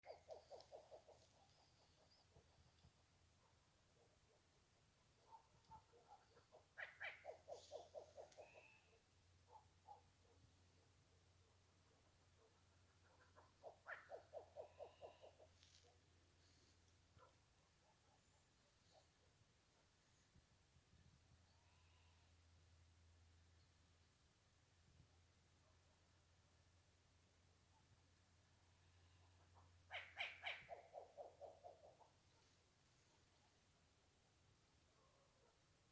Rufous-legged Owl (Strix rufipes)
Life Stage: Adult
Location or protected area: Sector de Santa María, Puerto Varas
Condition: Wild
Certainty: Observed, Recorded vocal
Concon--Strix-rufipes-.mp3